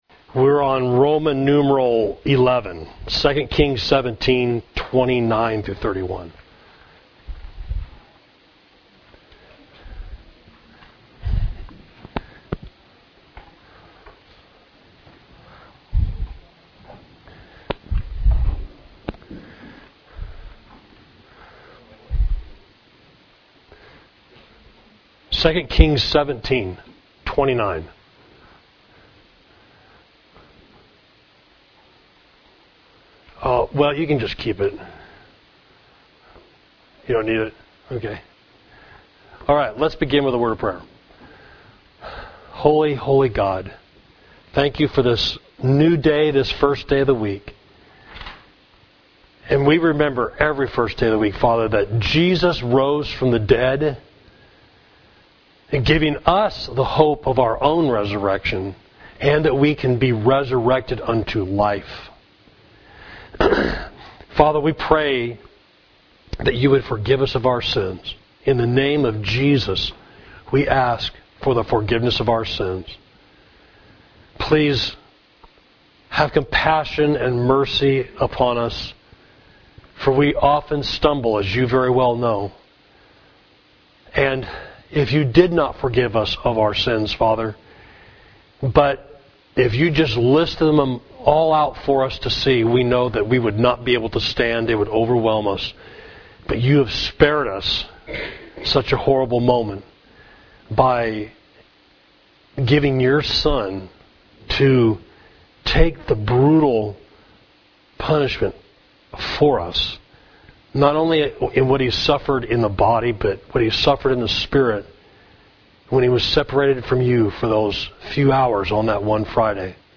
Class: Israel and Assyria, 2 Kings 17